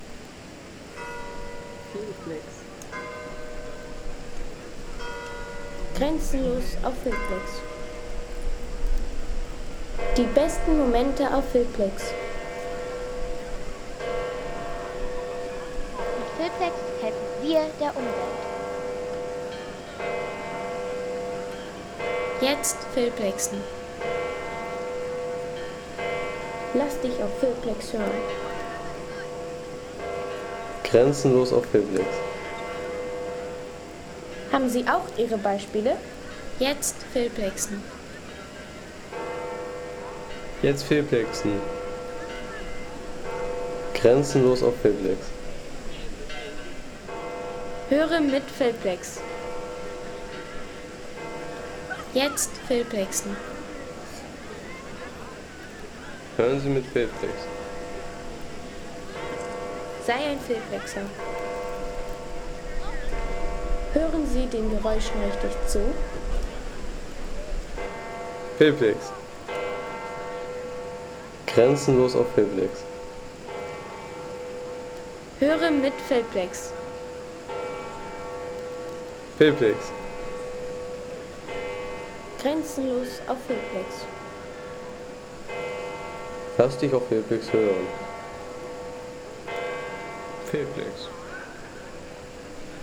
Das Geläut des Petersdoms
Kirchen